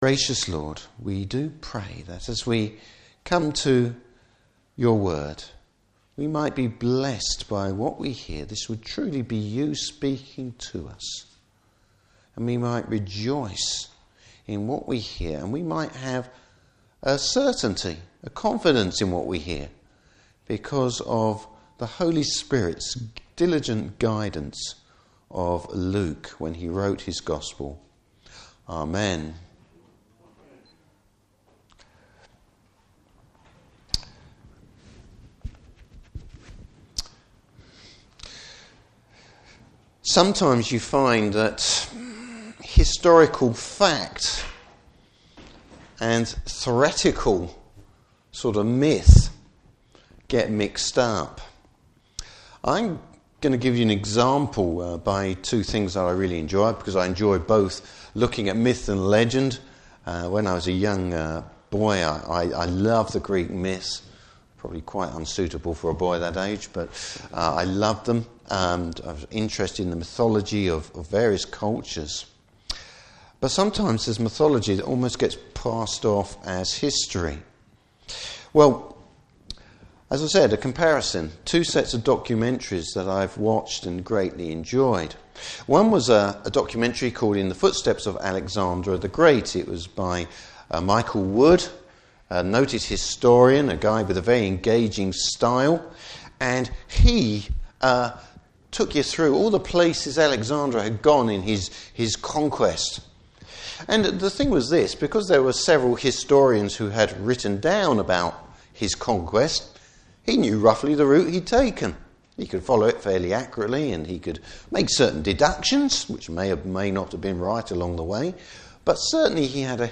Service Type: Morning Service Jesus the fulfilment of God’s purpose.